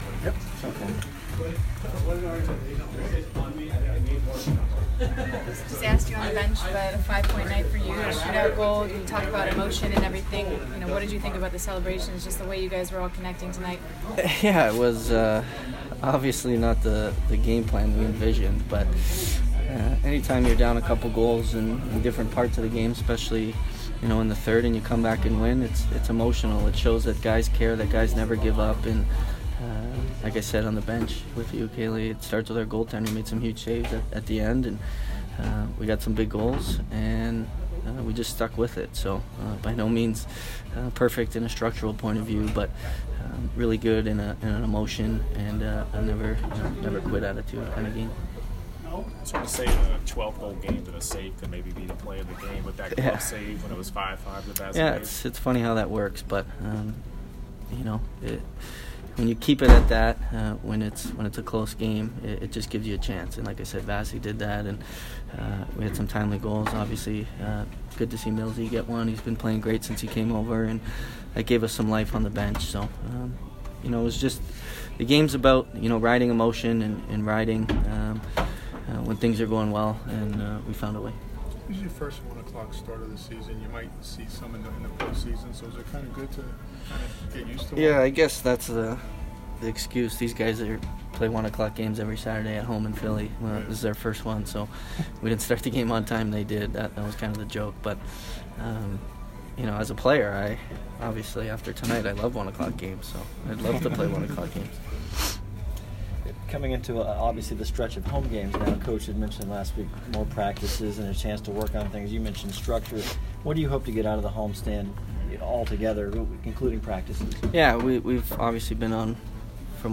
Steven Stamkos post-game 3/3